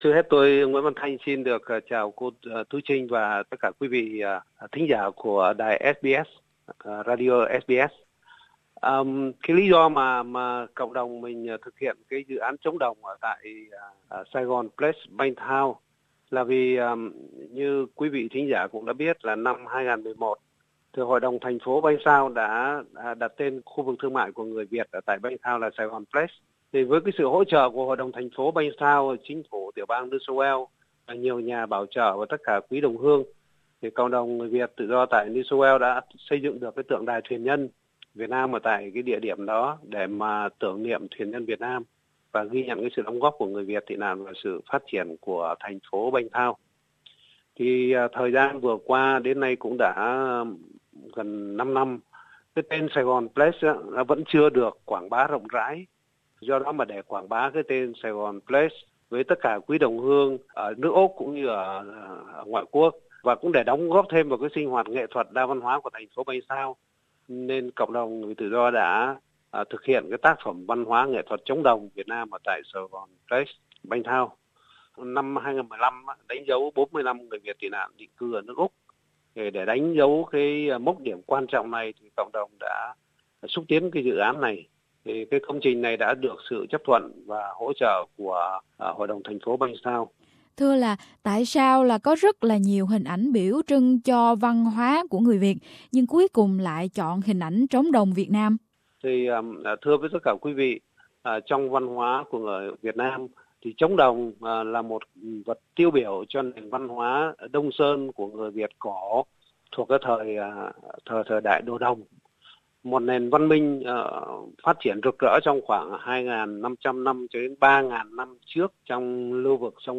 hỏi chuyện